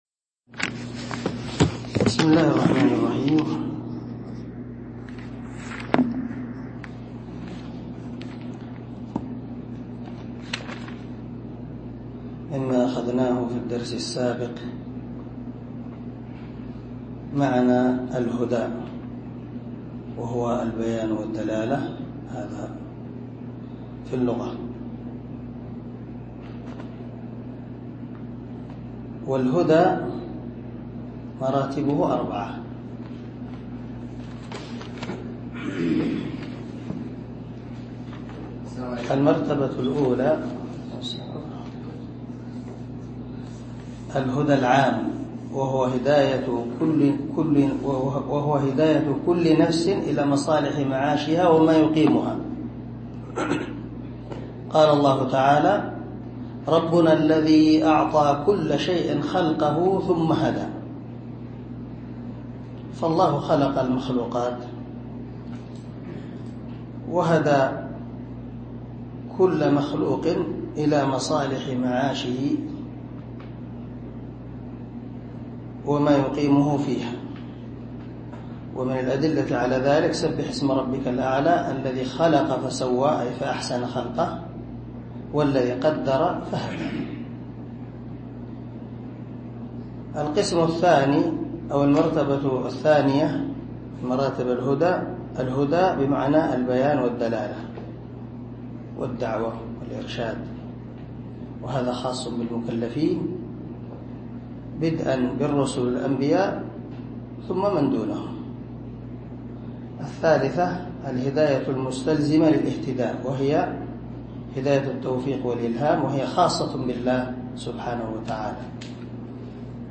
عنوان الدرس: الدرس السادس
دار الحديث- المَحاوِلة- الصبيحة.